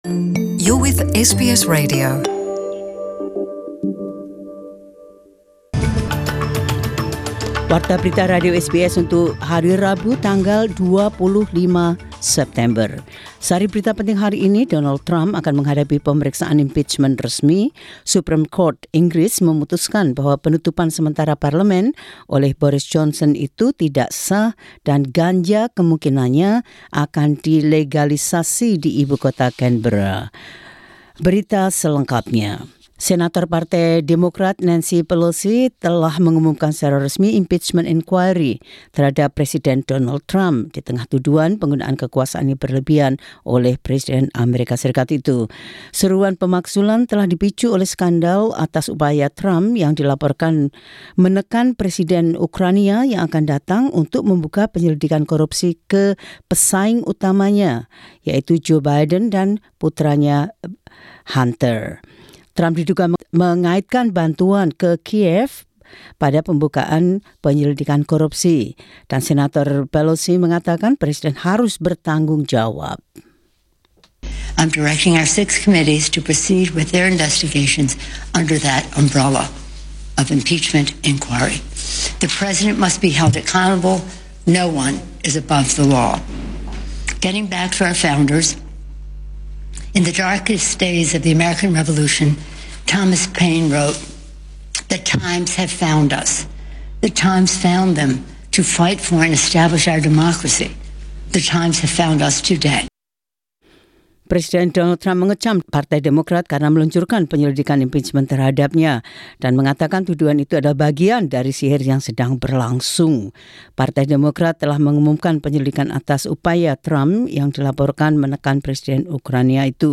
News Bulletin 25 Sep 2019 - dalam Bahasa Indonesia.